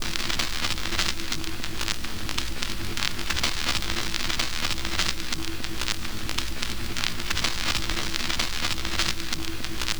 Night Rider - Album Noise.wav